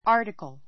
ɑ́ː r tikl ア ーティ クる